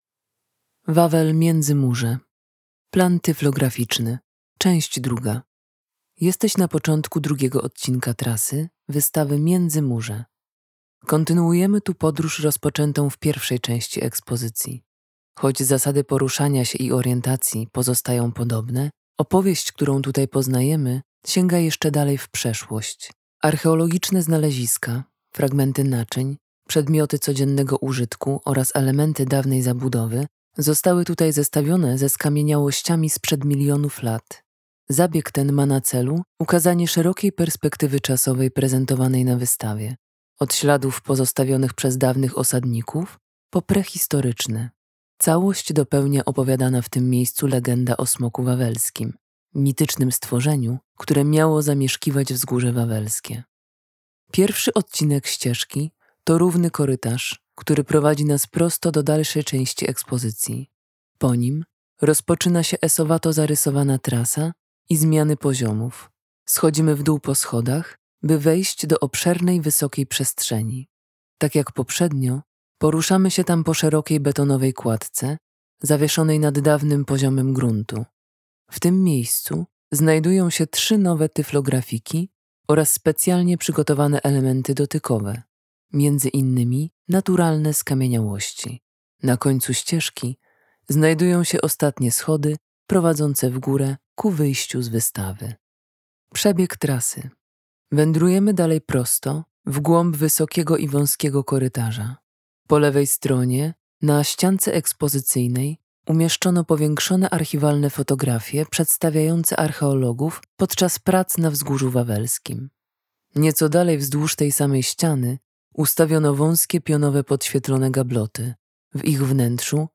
Audiodeskrypcja planu wystawy, część 2